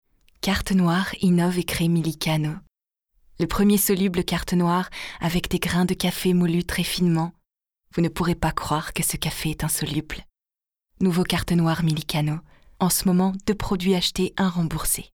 EXTRAITS VOIX
PUBLICITES /SLOGAN /BANDE ANNONCE